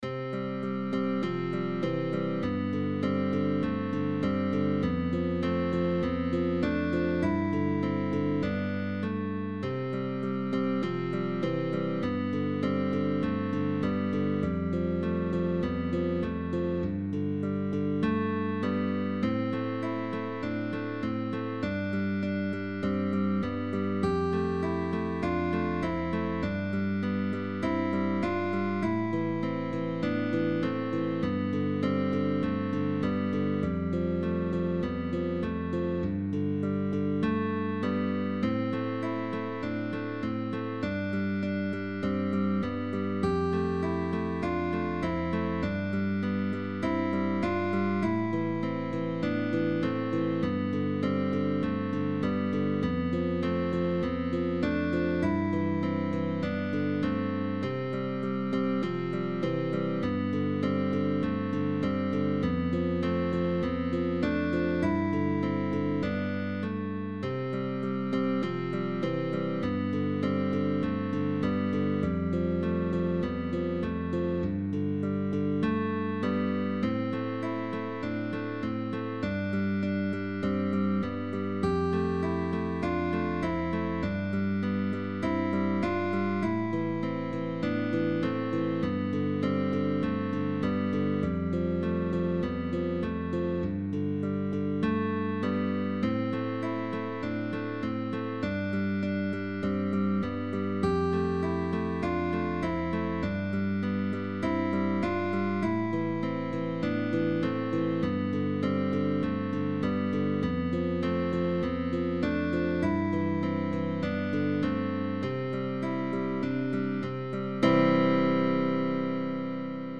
guitar trio
GUITAR TRIO